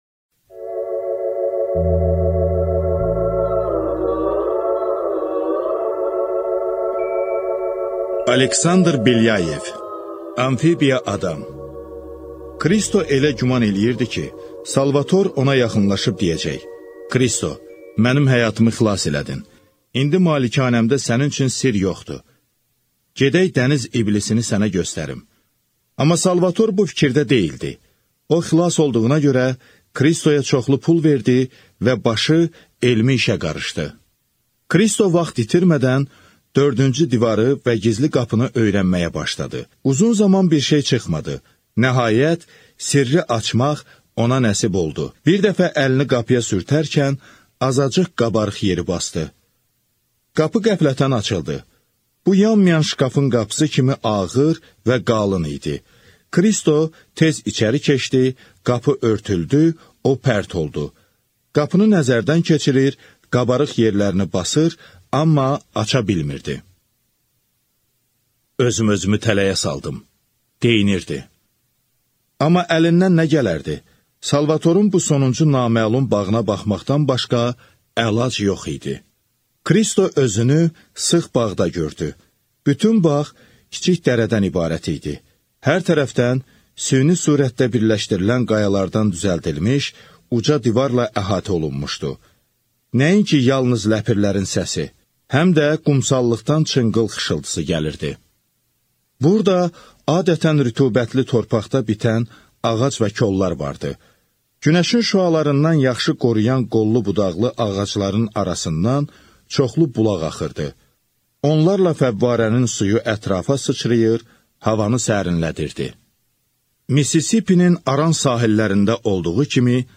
Аудиокнига Amfibiya adam | Библиотека аудиокниг